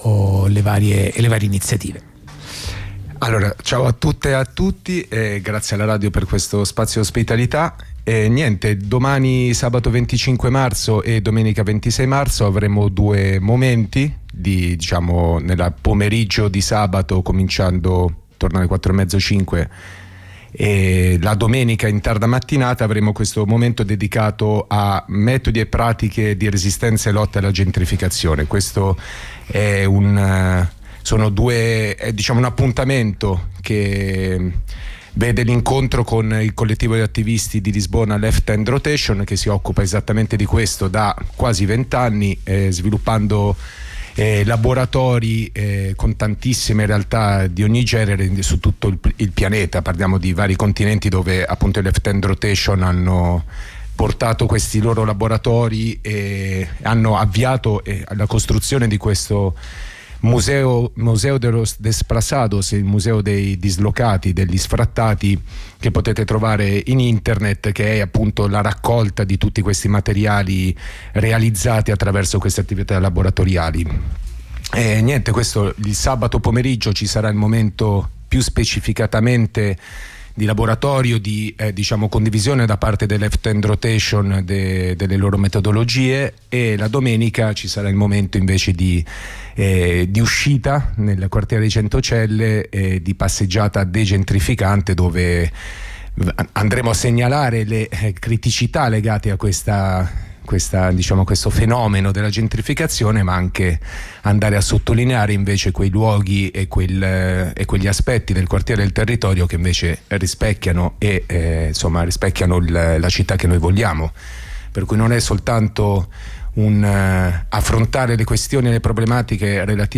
intervistacompagnealbanesi/intervistacompagnealbanesi